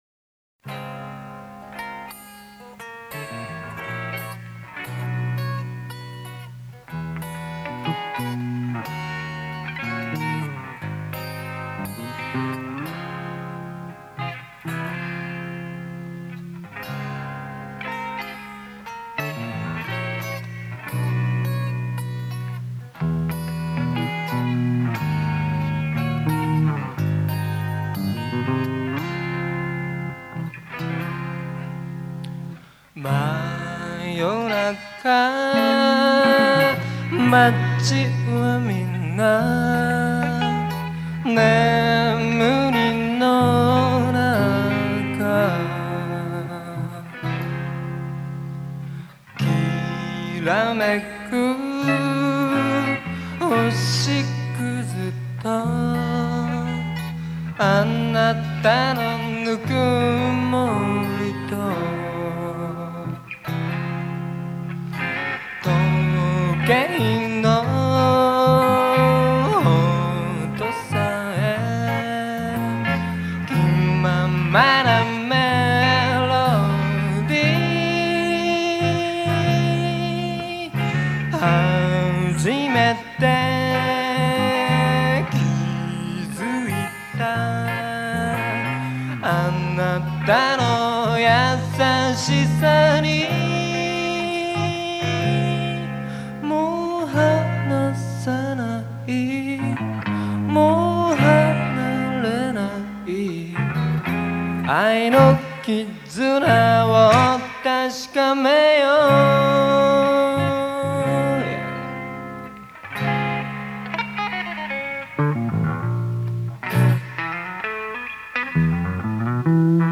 場所：神奈川公会堂
イベント名：横浜ふぉーく村コンサート